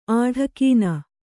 ♪ āḍhakīna